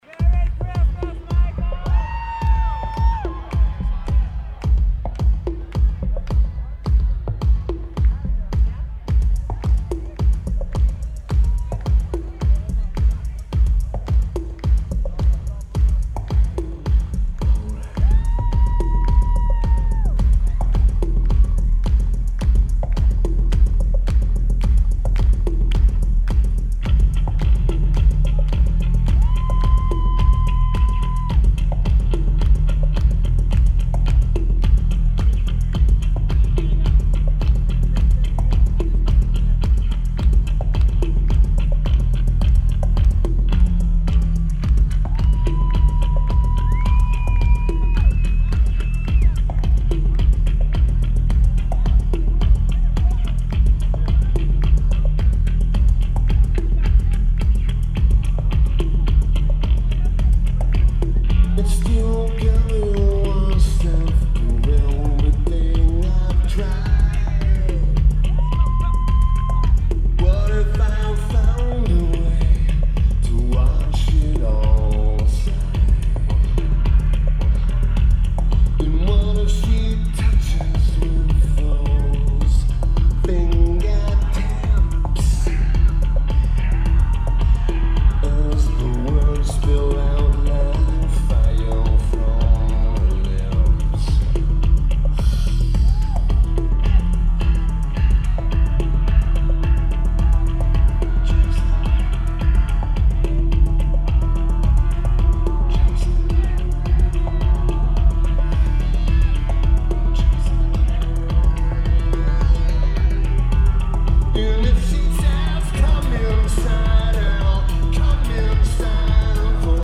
The Palladium
Los Angeles, CA United States
Lineage: Audio - AUD (DPA 4063 + Sony PCM-M10)